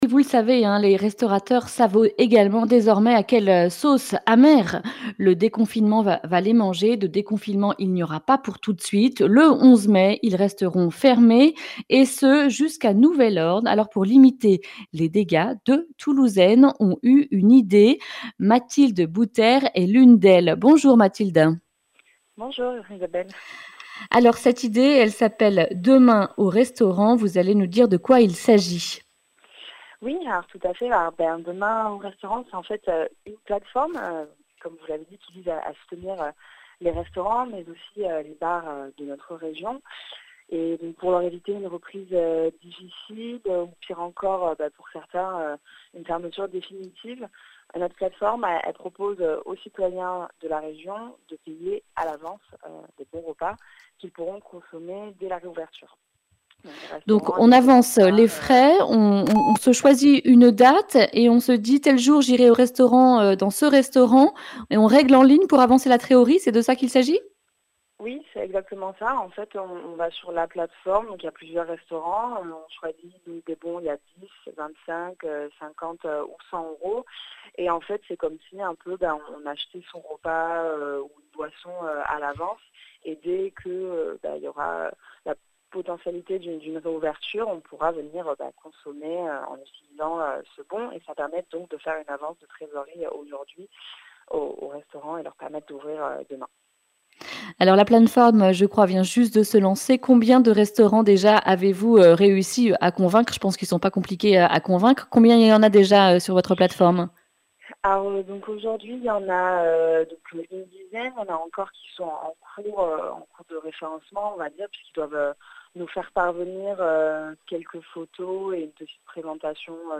lundi 4 mai 2020 Le grand entretien Durée 10 min
Une émission présentée par